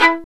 Index of /90_sSampleCDs/Roland L-CD702/VOL-1/STR_Violin 2&3vb/STR_Vln3 % marc
STR VLN JE0S.wav